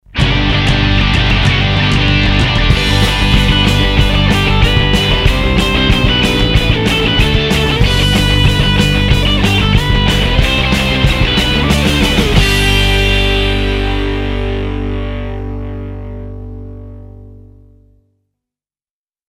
punk version
Me on guitar
bass
drums